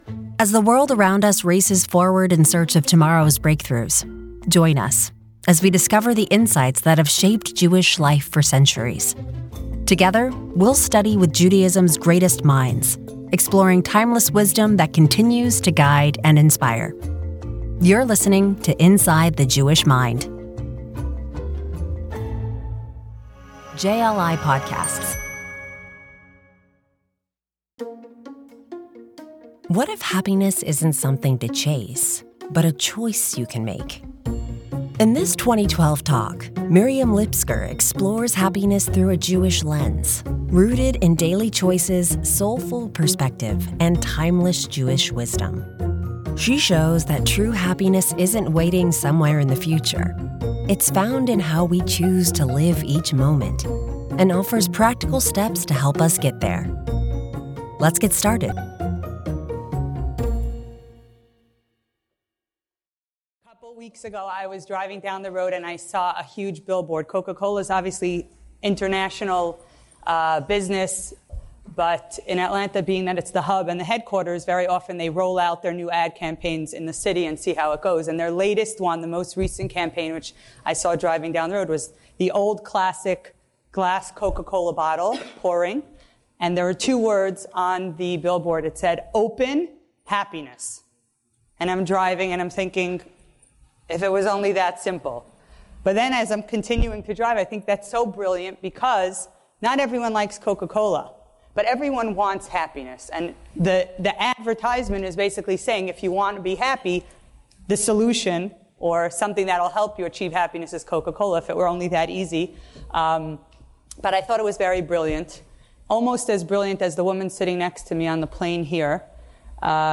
In this 2012 talk